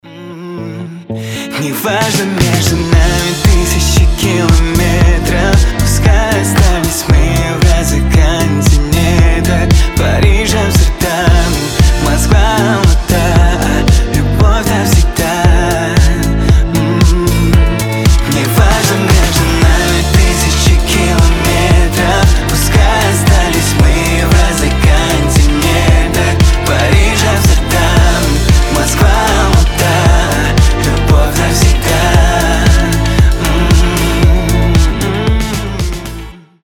мужской голос
приятные